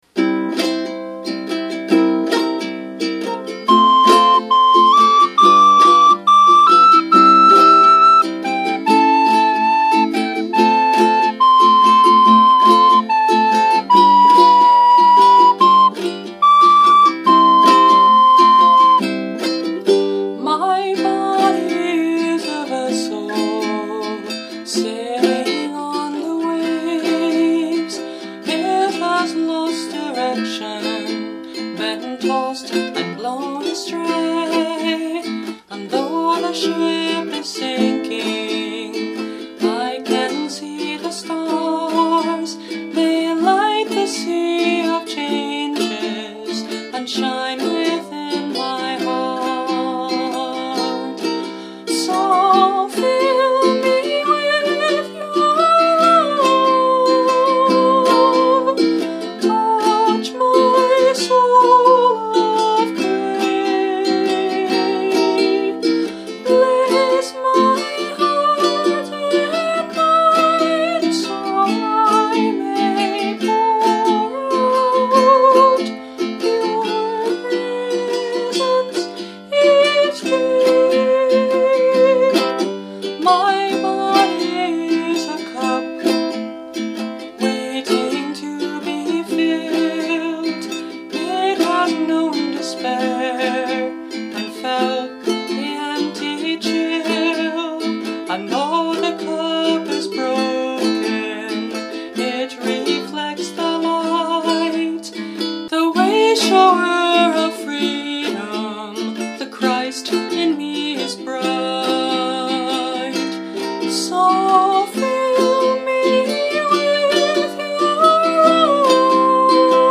Eventide – Mahogany Concert Ukulele
Soprano Recorder